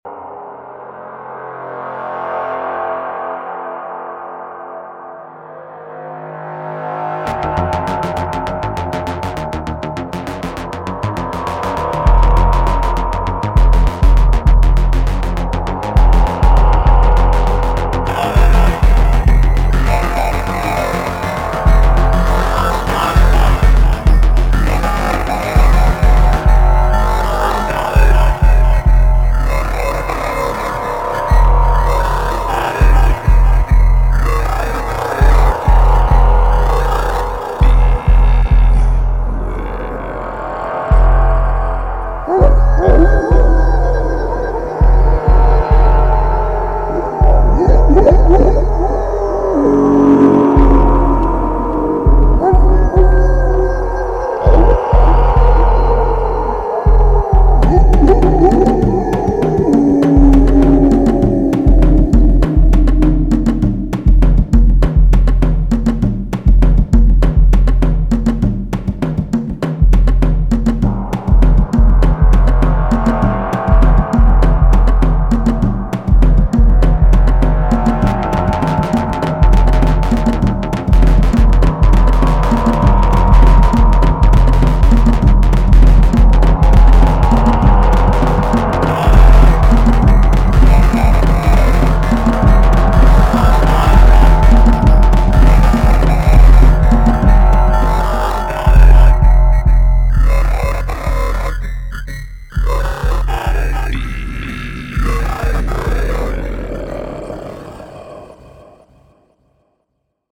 soundscape sound scape fx special fx